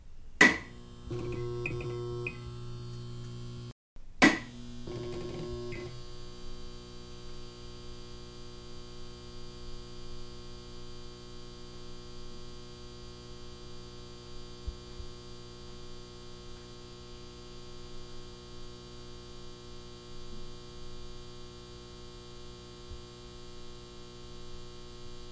随机的" 日光灯的开关，关闭房间里的电声嗡嗡声，单声道的环境 + 录音和飞行的结束
描述：荧光灯开关关闭宽敞的电动嗡嗡声单声道+颠簸和飞行end.wav
Tag: 宽敞 荧光灯 嗡嗡声 开关